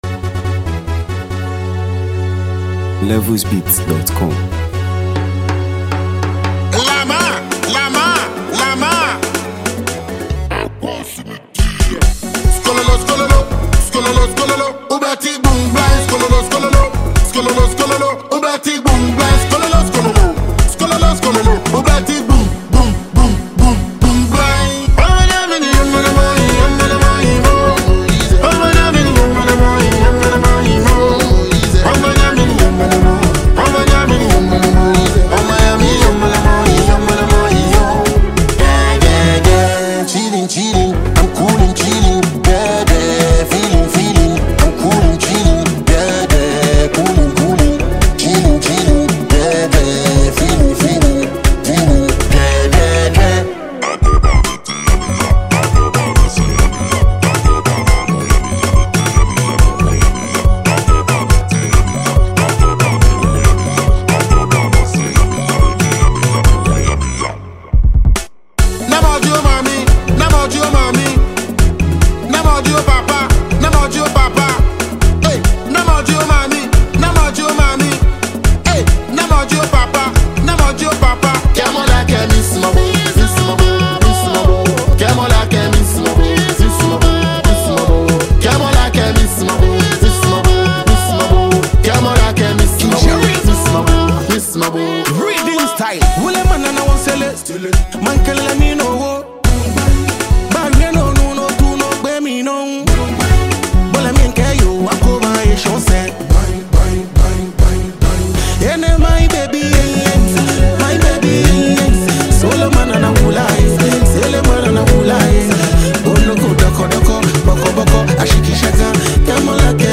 A Smooth Afro-Dancehall Vibe
A Relaxed Sound with Global Appeal